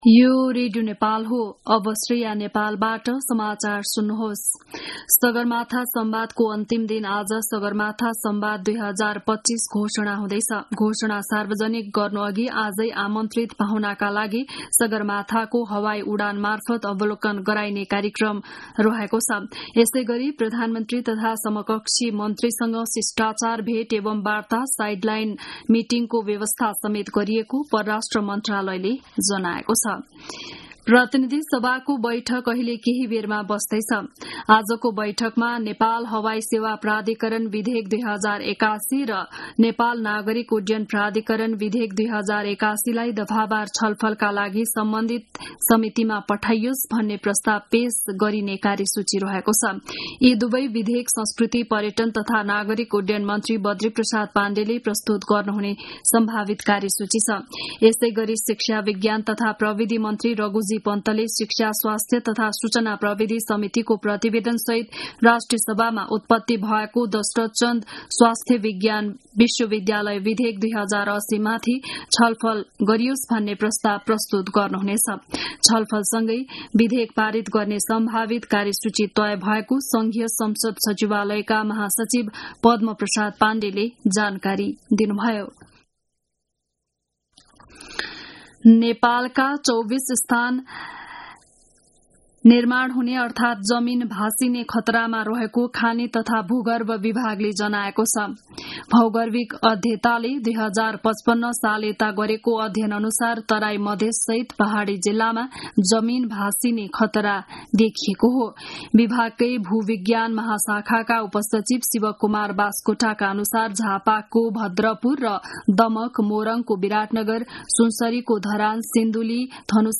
बिहान ११ बजेको नेपाली समाचार : ४ जेठ , २०८२
11-am-news-1-4.mp3